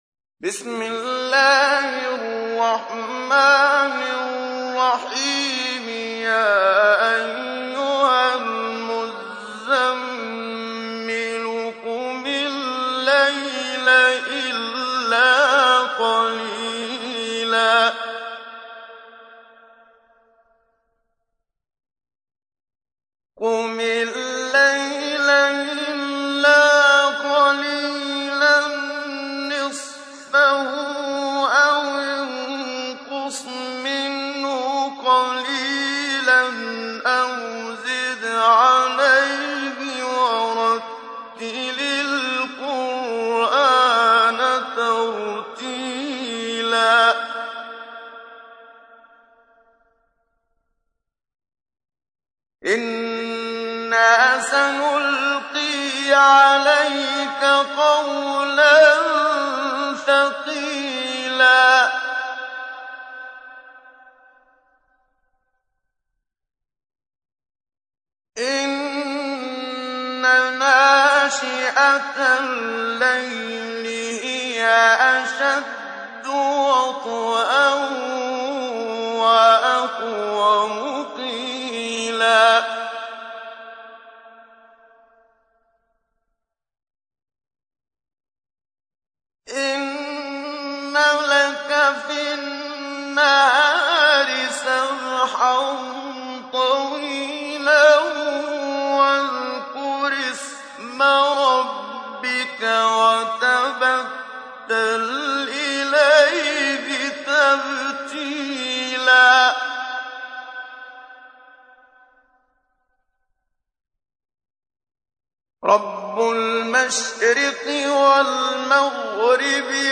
تحميل : 73. سورة المزمل / القارئ محمد صديق المنشاوي / القرآن الكريم / موقع يا حسين